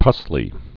(pŭslē)